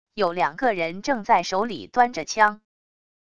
有两个人正在手里端着枪wav音频生成系统WAV Audio Player